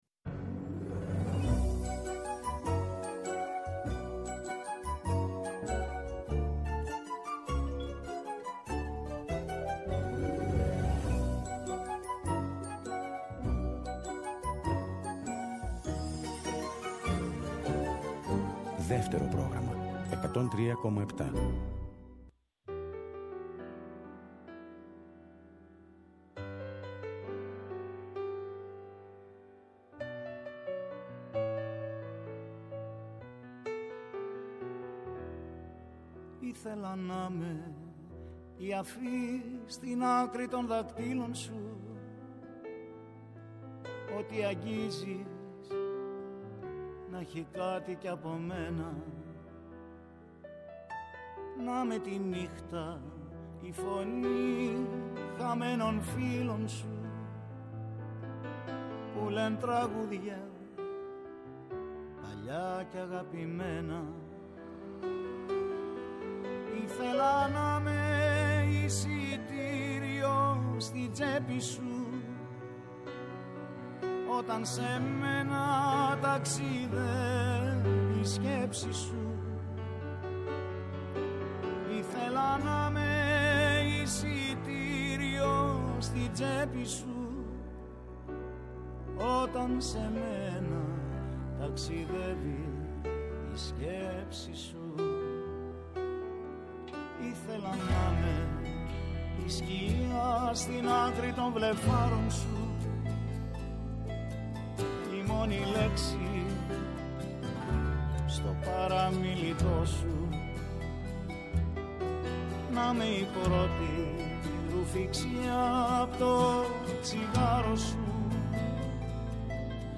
ΔΕΥΤΕΡΟ ΠΡΟΓΡΑΜΜΑ Παντος Καιρου Συνεντεύξεις Αινιγματα Γιωργος Καζαντζης Δημητρης Ζερβουδακης Κωστας Φασουλας Παντελης Θαλασσινος